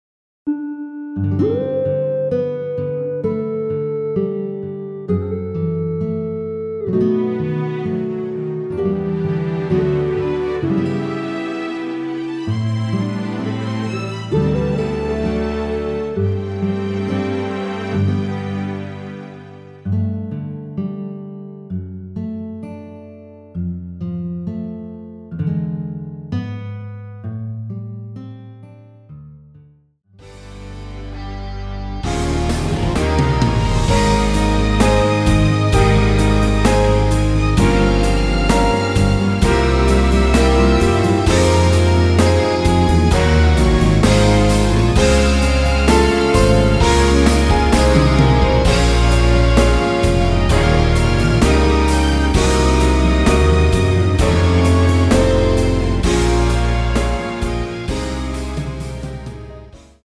◈ 곡명 옆 (-1)은 반음 내림, (+1)은 반음 올림 입니다.
앞부분30초, 뒷부분30초씩 편집해서 올려 드리고 있습니다.
중간에 음이 끈어지고 다시 나오는 이유는